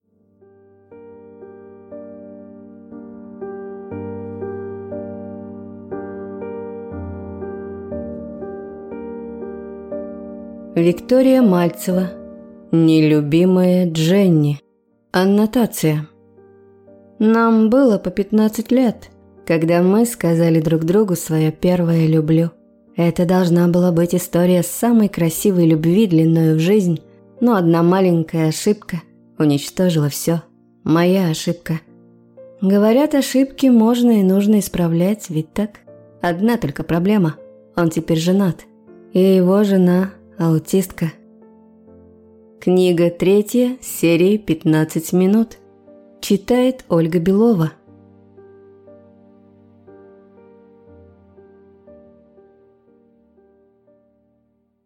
Аудиокнига Нелюбимая Дженни | Библиотека аудиокниг
Прослушать и бесплатно скачать фрагмент аудиокниги